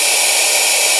rr3-assets/files/.depot/audio/sfx/forced_induction/turbo_04.wav
turbo_04.wav